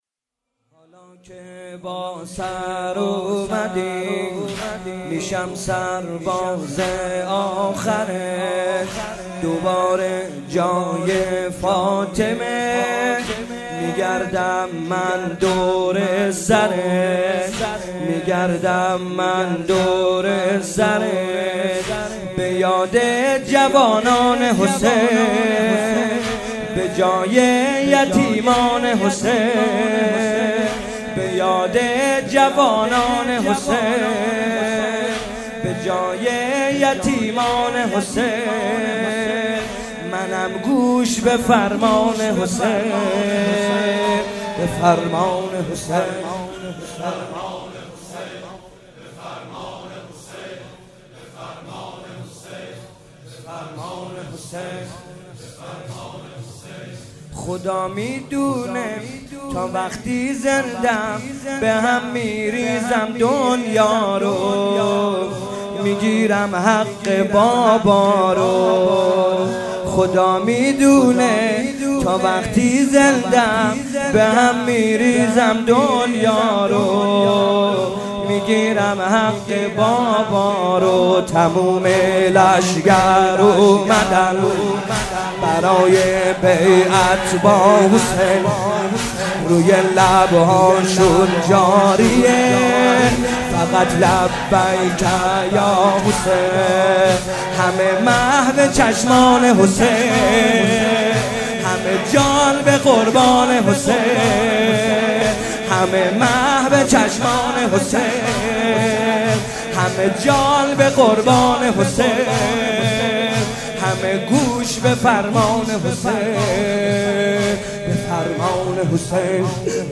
شب سوم محرم - به نام نامیِ حضرت رقیه(س)
محرم 95 | واحد | هاکه باسراومدیم